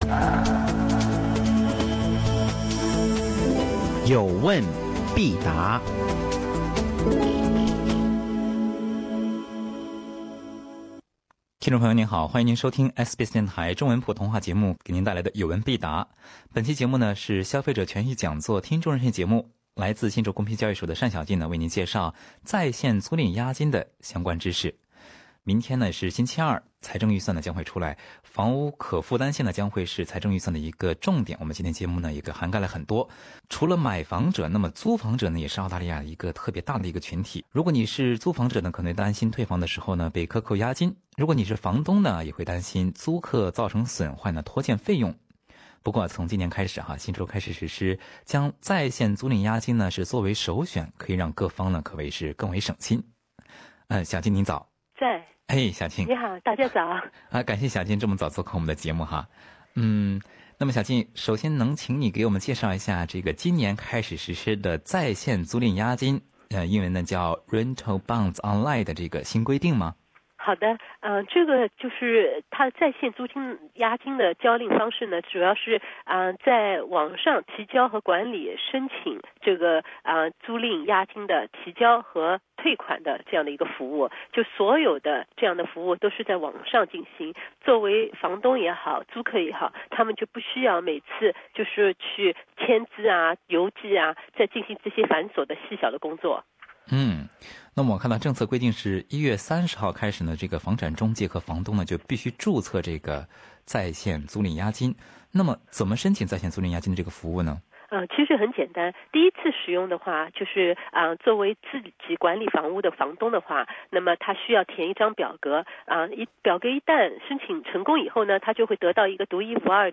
听众热线节目